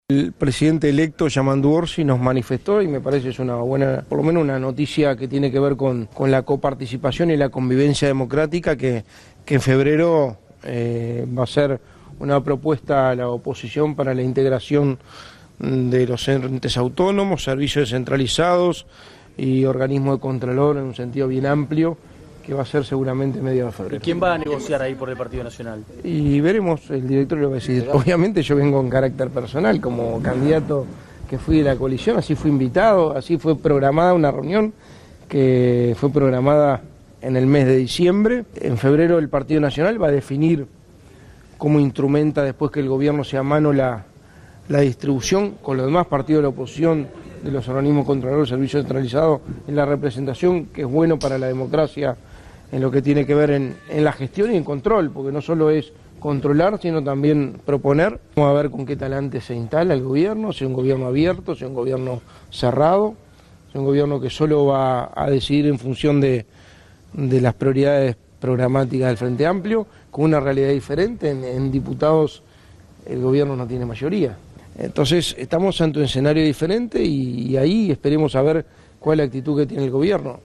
Declaraciones de Álvaro Delgado
A la salida del encuentro Delgado aclaró en rueda de prensa que concurrió a la reunión a título personal y no en representación del Partido Nacional.